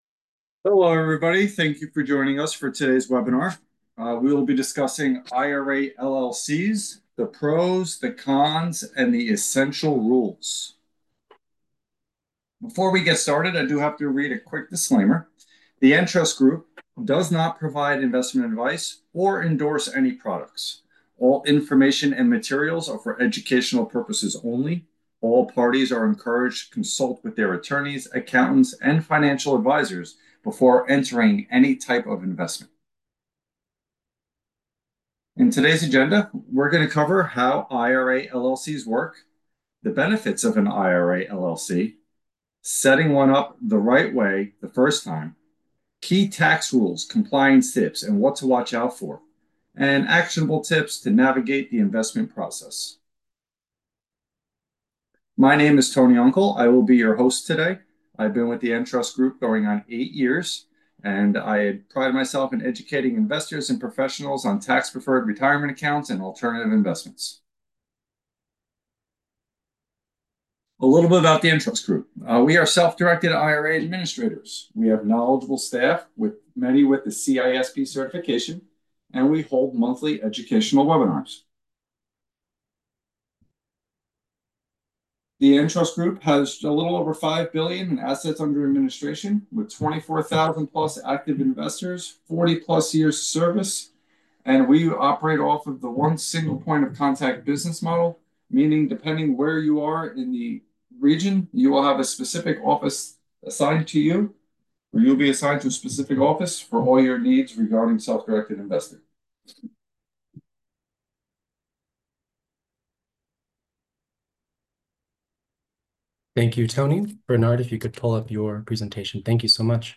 This webinar will break it all down—how it works, when it makes sense, and what to watch out for.
Date: March 19, 2025Duration: 1 Hour 26 Minutes (including 24-minute Q&A starting at 1:01:15)Presenters:
Webinar_March_2025_Audio.m4a